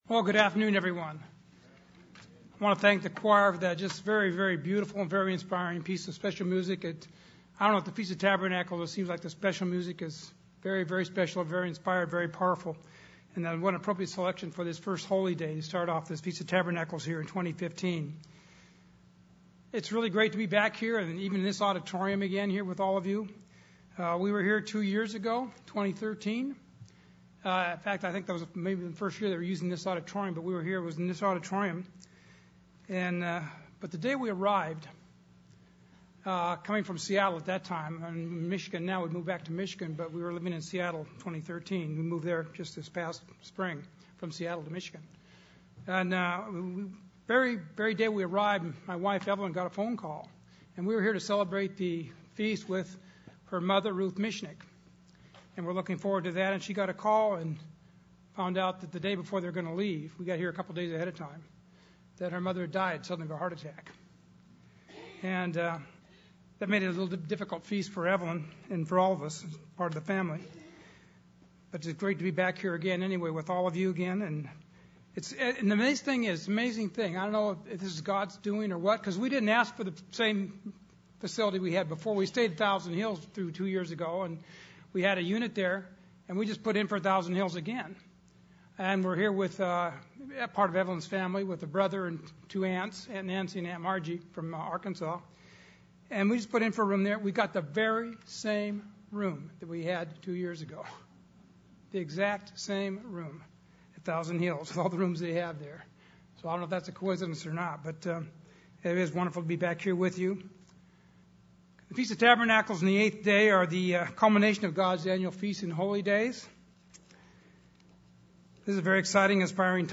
This sermon was given at the Branson, Missouri 2015 Feast site.